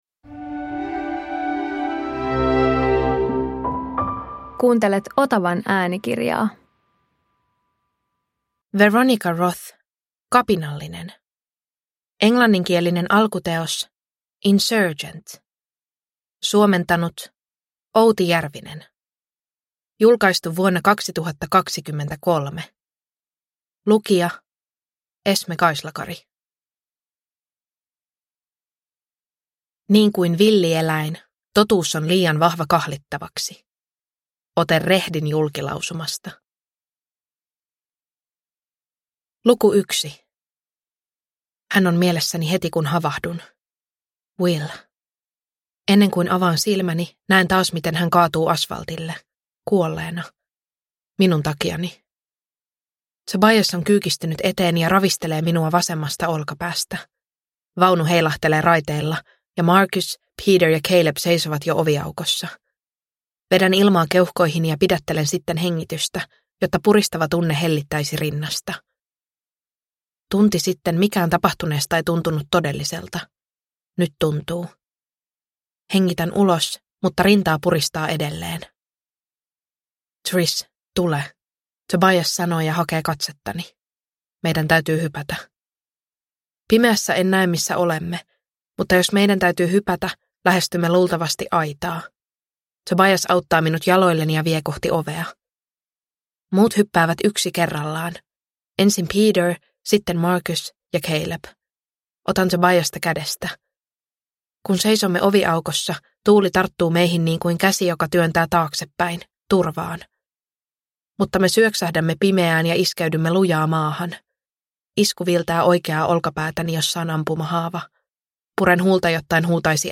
Kapinallinen – Ljudbok – Laddas ner